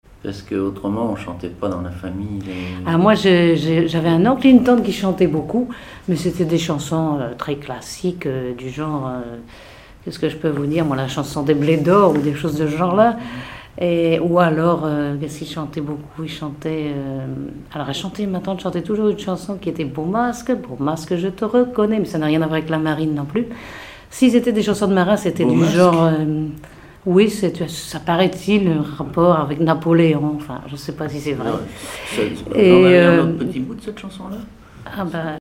chanteur(s), chant, chanson, chansonnette
Conversation sur les musiciens de Saint-Pierre et Miquelon
Catégorie Témoignage